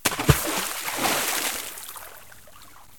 MissSFX.ogg